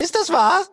Kategorie:Fallout 2: Audiodialoge Du kannst diese Datei nicht überschreiben.